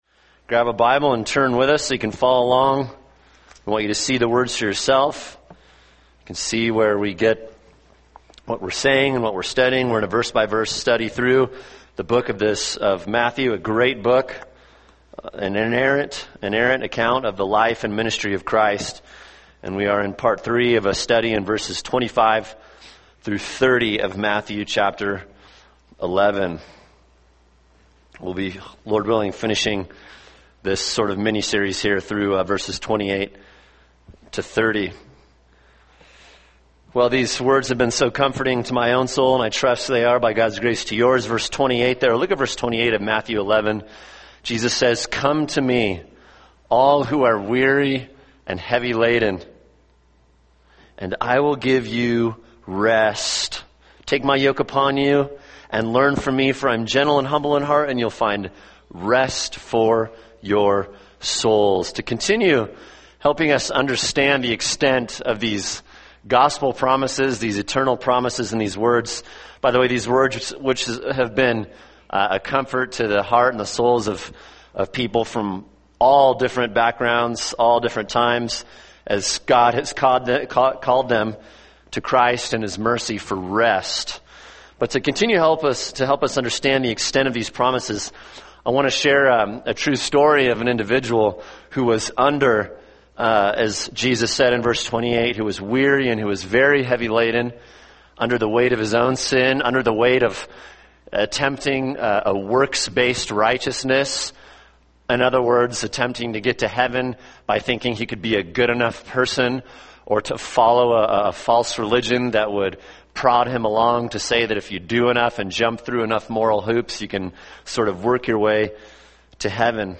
[sermon] Matthew 11:28-30 – God’s Sovereign Grace (part 3) | Cornerstone Church - Jackson Hole